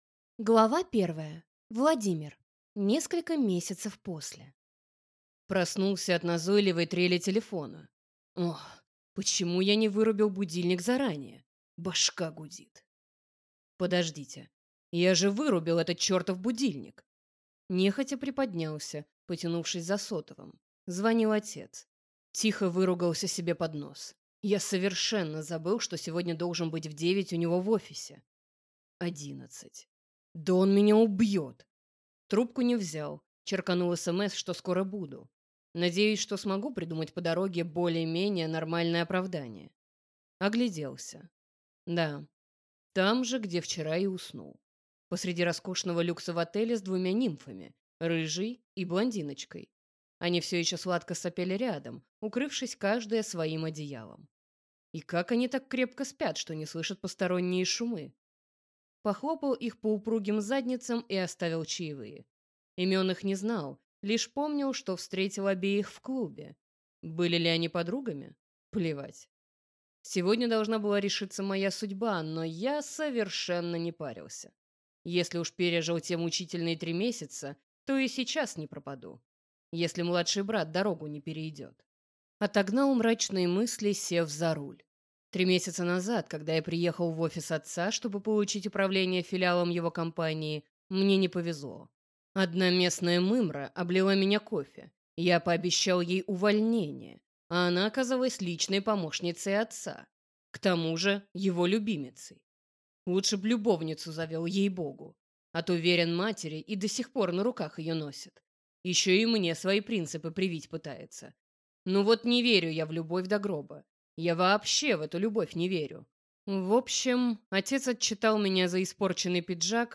Аудиокнига «Бывшие: второй шанс на любовь».